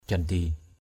/ʥal-d̪i/ (cv.) jalidi jl{d} (d.) đại dương = océan. ocean. parap rabang mâng mâh jaldi (DWM) prP rb/ m/ mH jLd} người ta làm cầu bằng vàng biển = on...